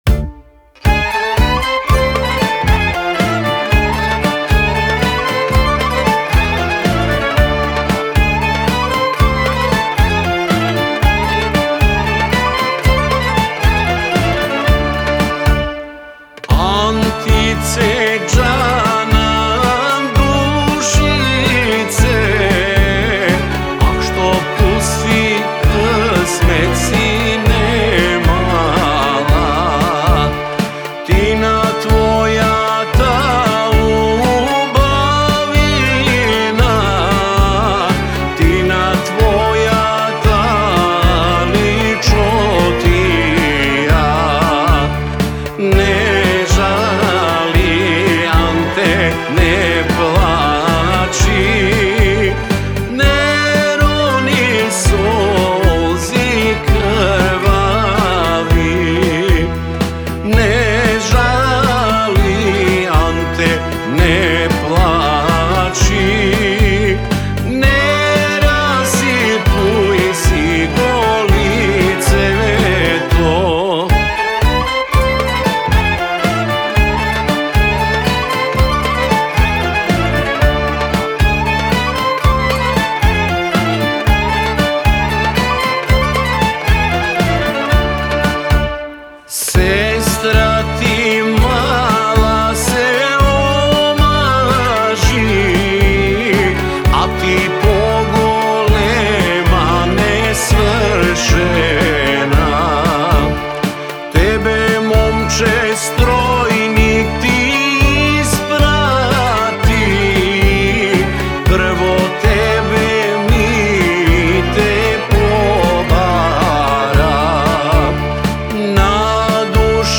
народна македонска песна